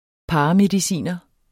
Udtale [ ˈpɑːɑ- ]